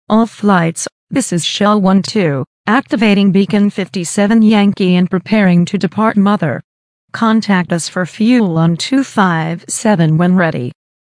Shell12Startup.ogg